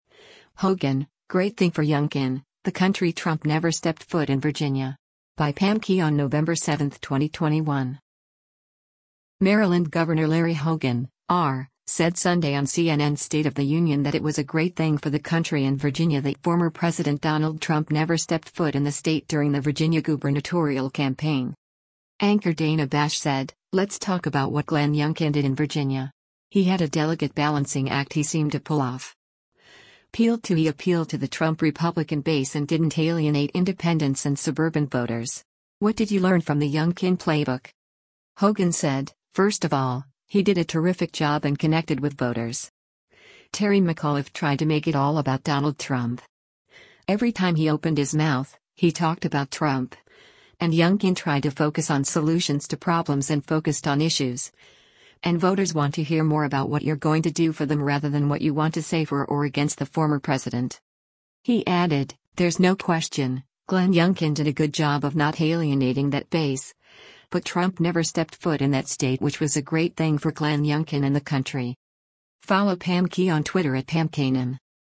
Maryland Governor Larry Hogan (R) said Sunday on CNN’s “State of the Union” that it was a great thing for the country and Virginia that former president Donald Trump “never stepped foot in the state” during the Virginia gubernatorial campaign.